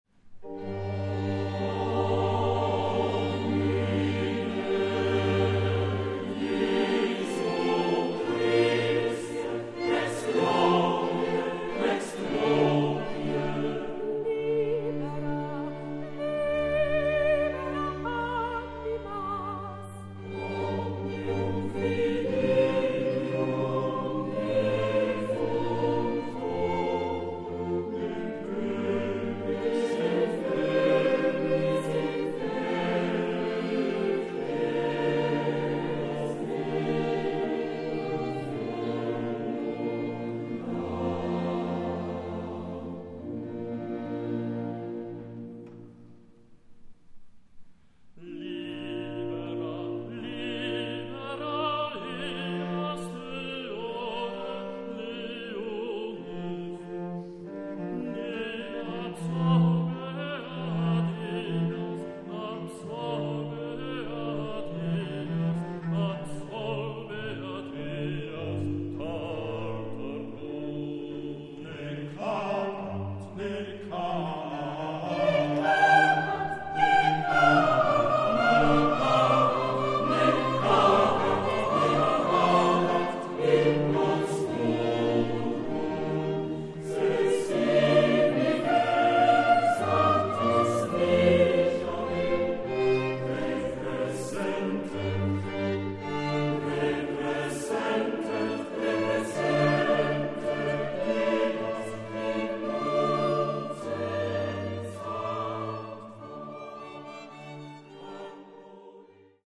— Ausschnitte aus dem Konzert der Kaufbeurer Martinsfinken in Irsee vom 21.3.10: